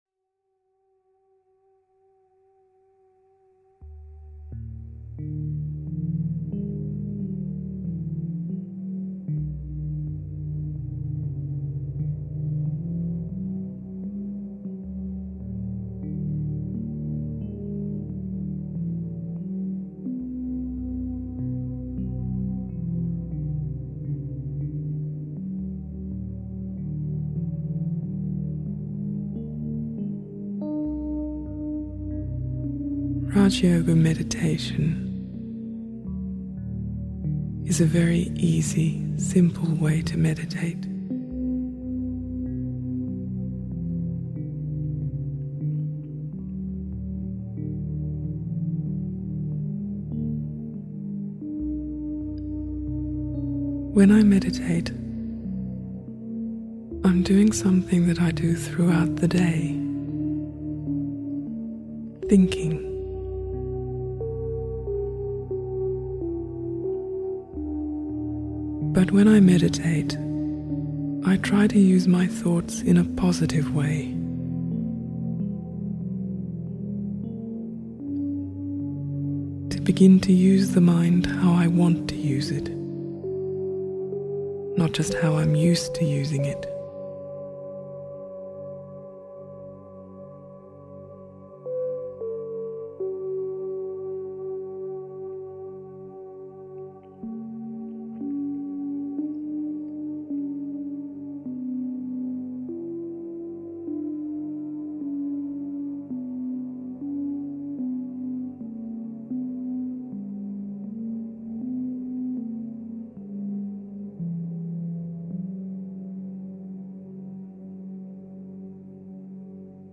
"Learn How to Meditate" by Brahma Khumaris is a musical, guided meditation. It offers a simple introduction to meditation, with clear instruction to help you unwind and calm your mind.